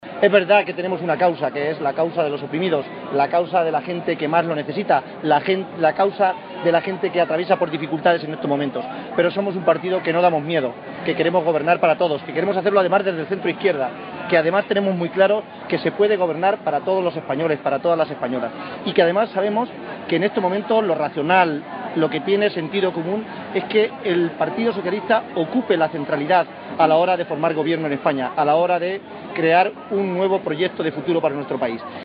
Acto público en Puertollano
Cortes de audio de la rueda de prensa